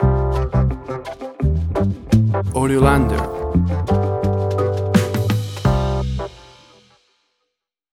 WAV Sample Rate: 16-Bit stereo, 44.1 kHz
Tempo (BPM): 85